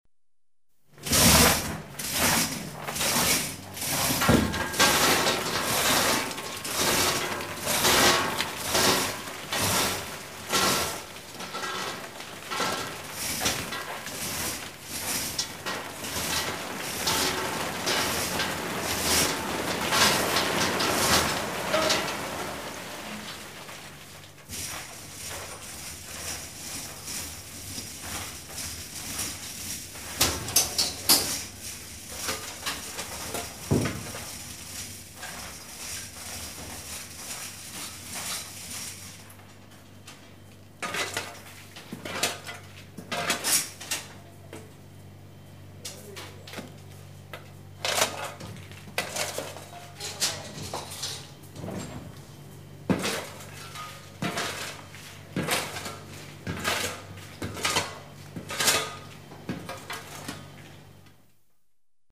Звук загрузки угля на корабль